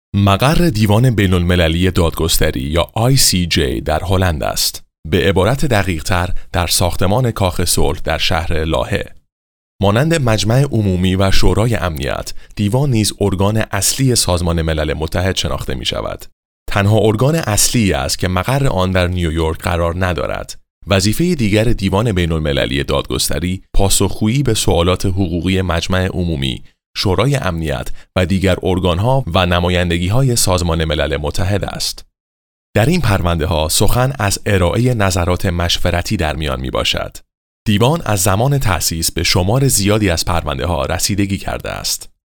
纪录片【大气磁性】